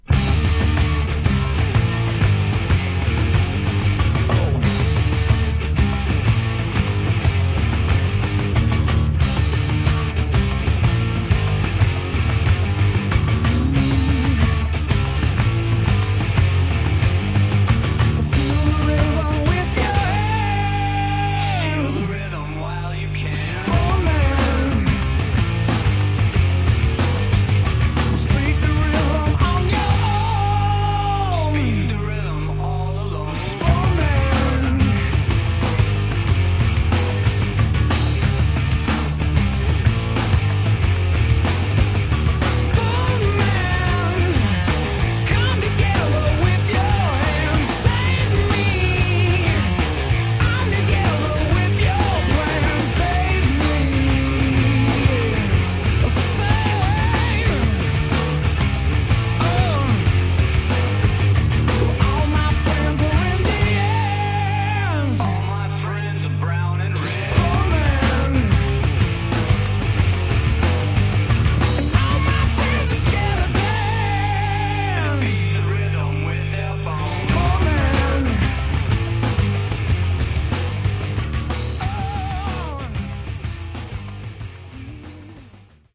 Genre: Punk/Grunge